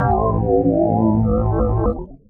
20SYN.BASS.wav